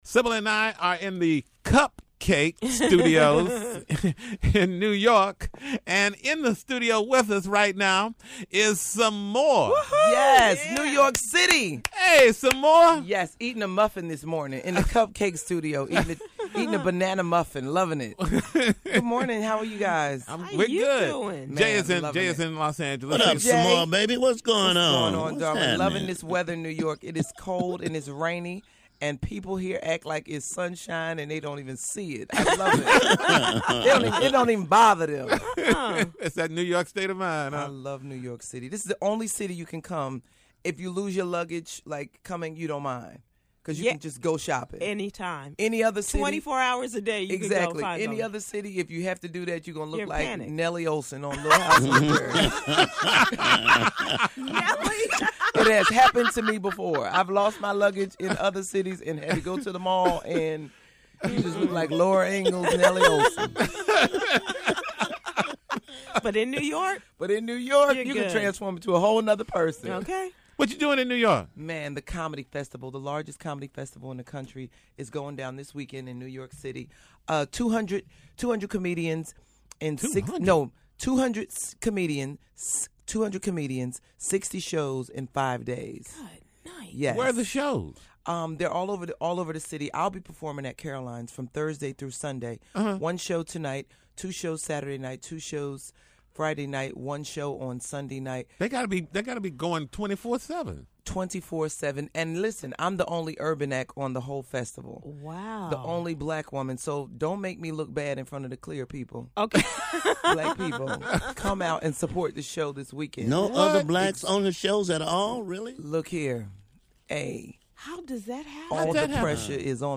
Comedian Sommore, host of BET's Comicview, talks about her various projects.